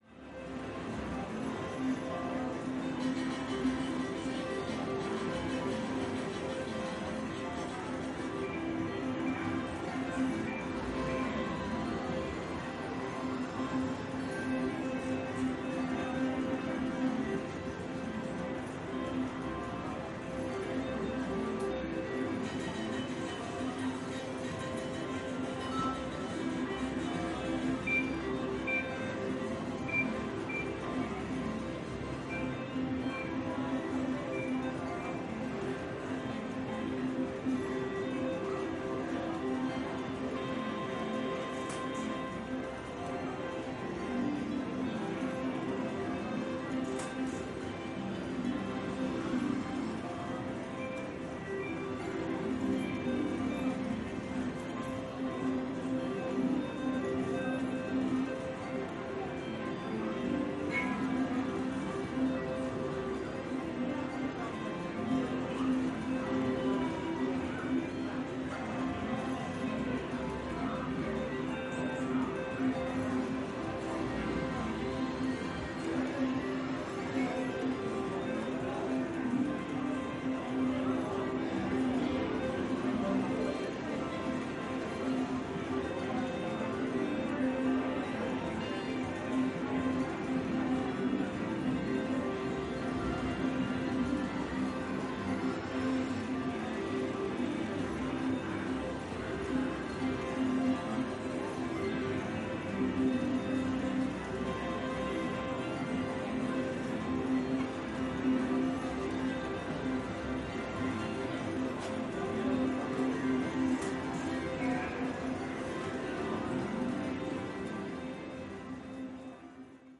ambience.mp3